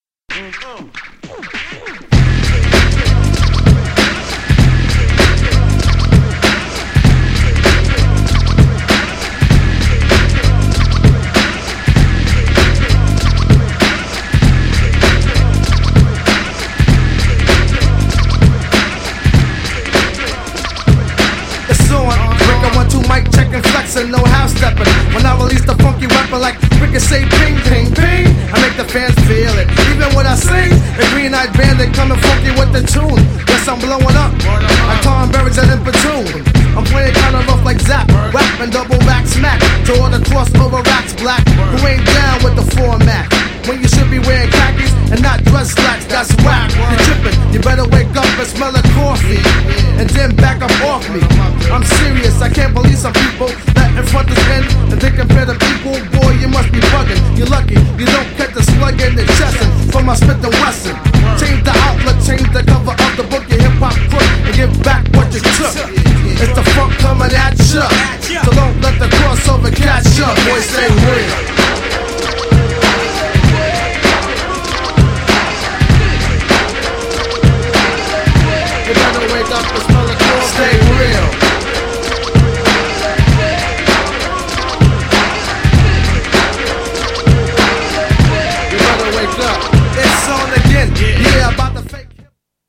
相変わらずのドス黒いFUNKネタヘビーな曲ばかり!!
GENRE Hip Hop
BPM 96〜100BPM